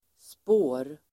Uttal: [spå:r]